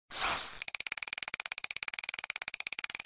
fishreel.ogg